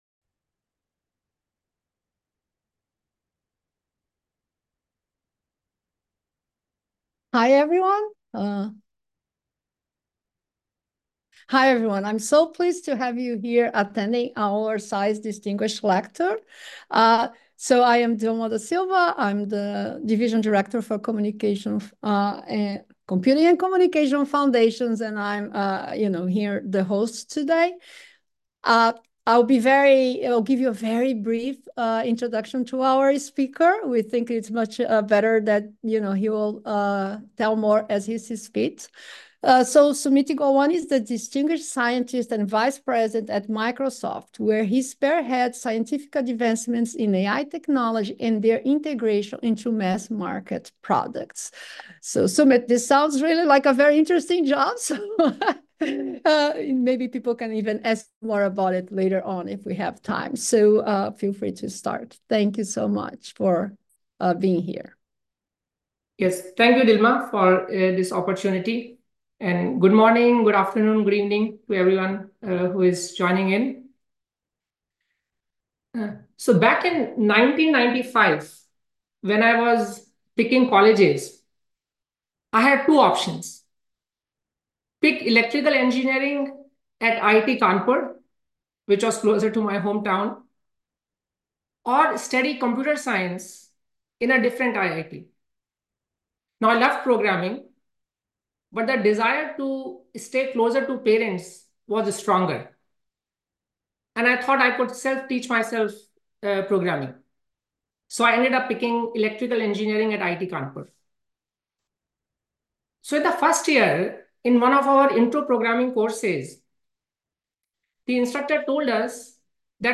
CISE Distinguished Lecture Series